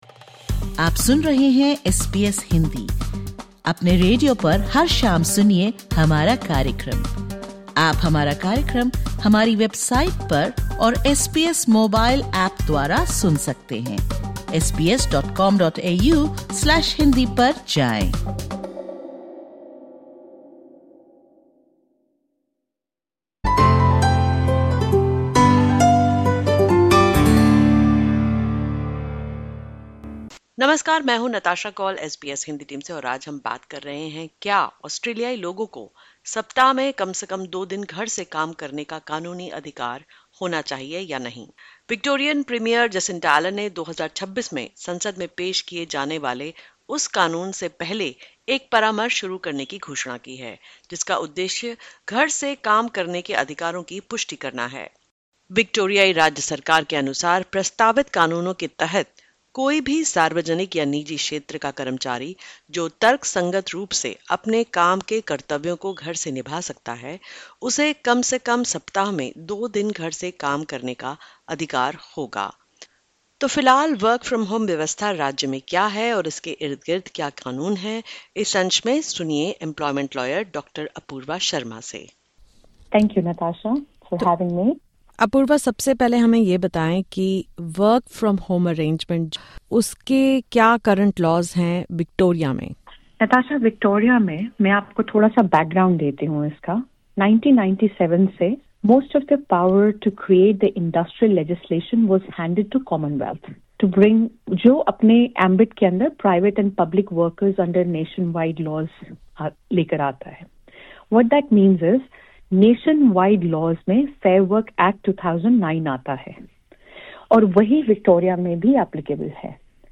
a legal expert